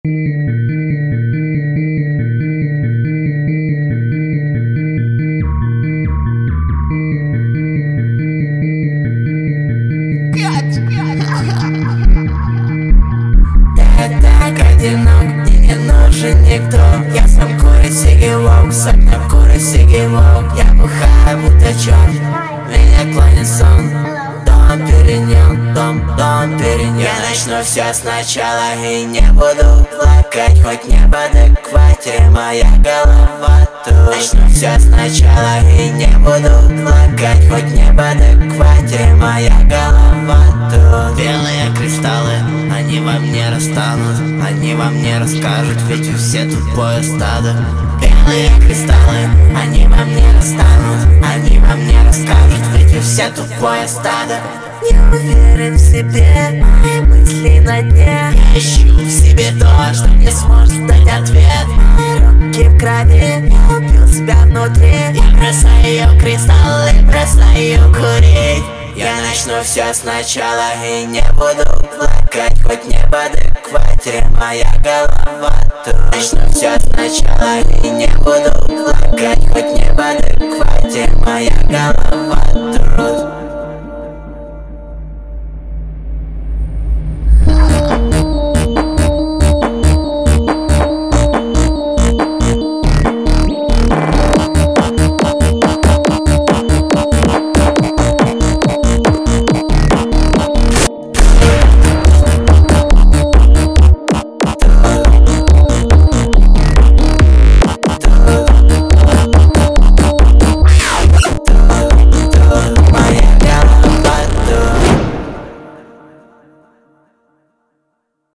Рэп, Новинки